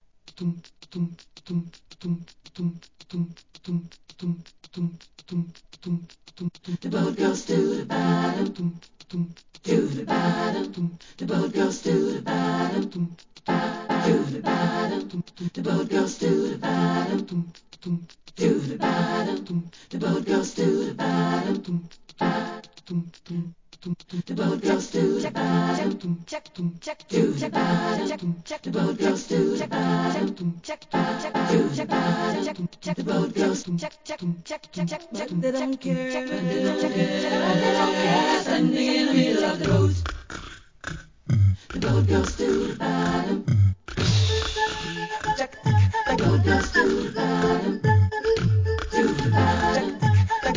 HIP HOP/R&B
アカペラ・グループ!! ヒューマンBEAT BOXでのREMIX VER.もNICE!!